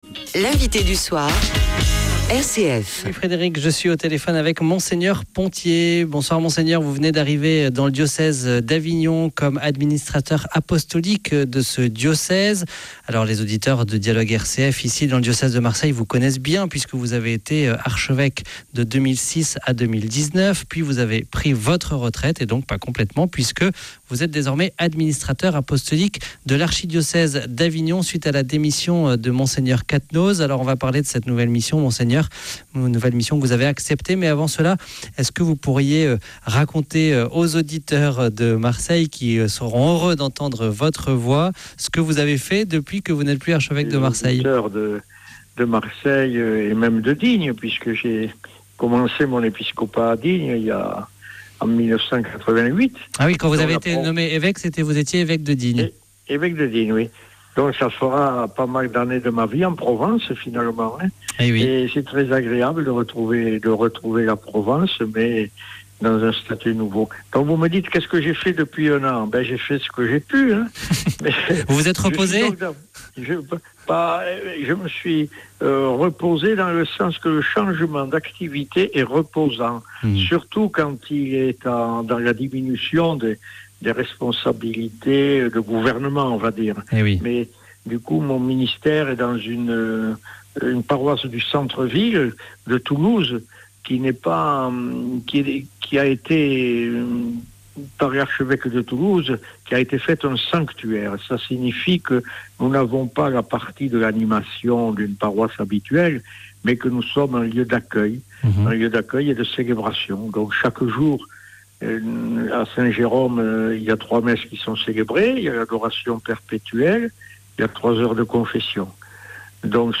Entretien avec Mgr Pontier : l'Eglise d'Avignon, c'est ensemble !